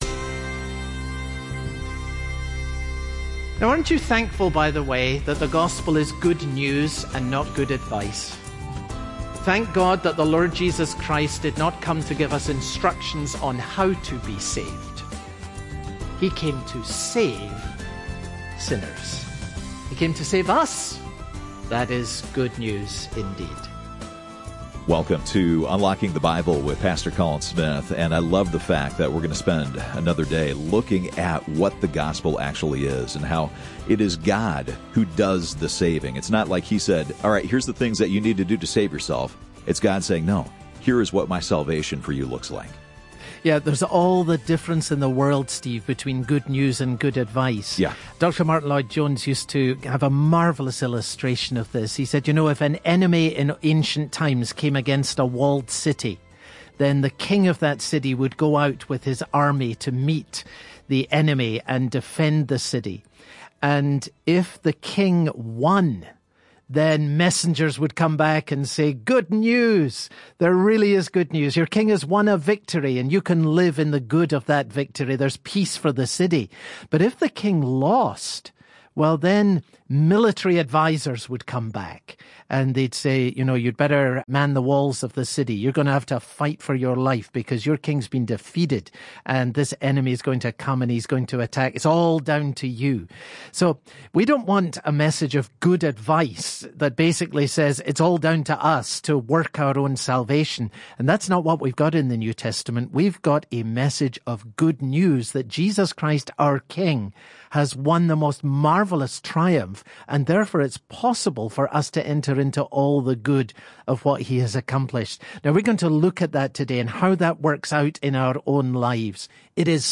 Part 1 1 Timothy Broadcast Details Date Feb 11